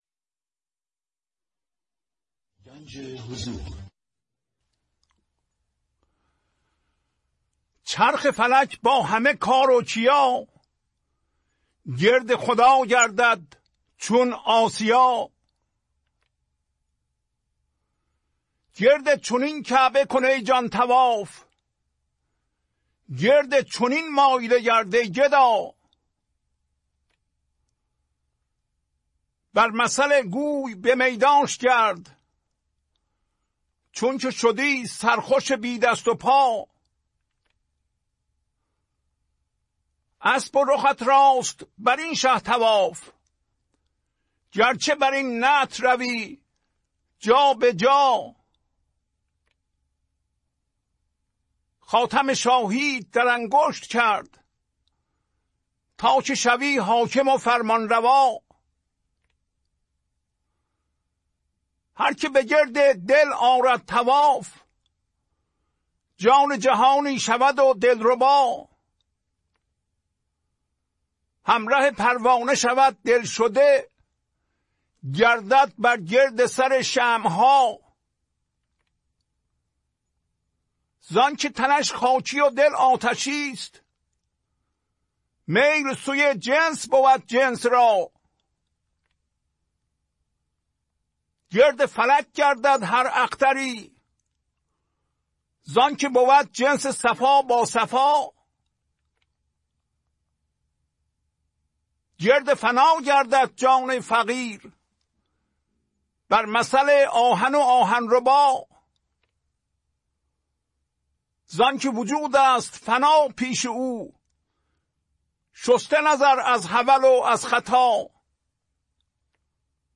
خوانش تمام ابیات این برنامه - فایل صوتی
992-Poems-Voice.mp3